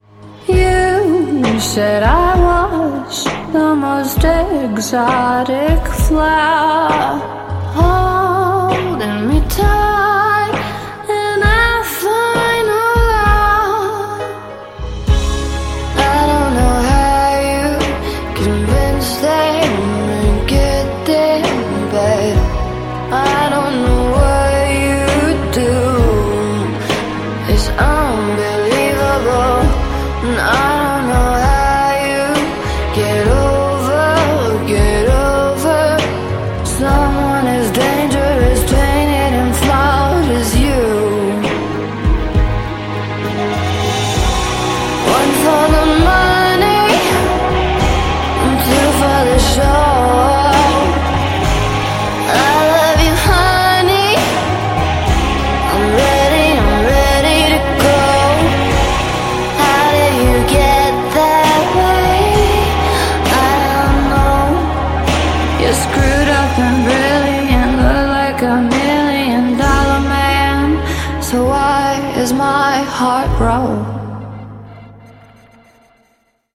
Un pezzo denso di stile ed eleganza d’altri tempi.